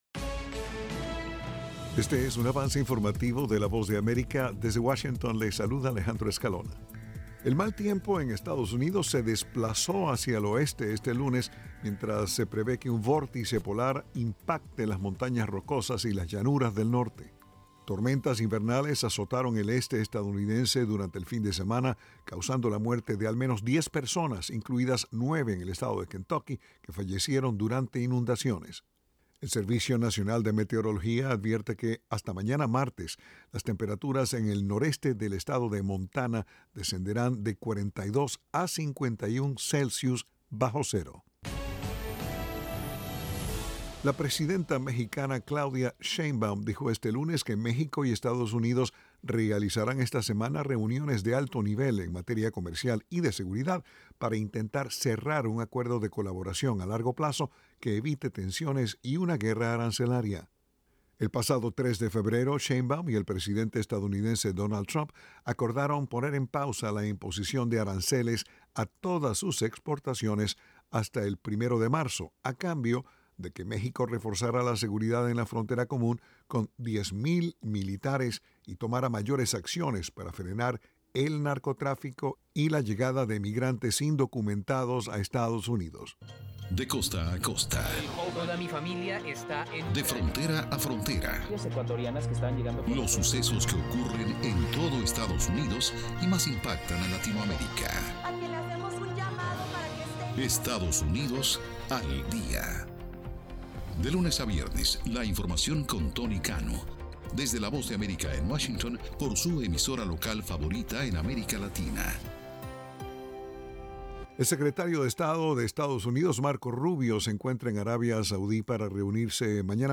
El siguiente es un avance informativo de la Voz de América.
"Avance Informativo" es un segmento de noticias de la Voz de América para nuestras afiliadas en la región de América Latina y el Caribe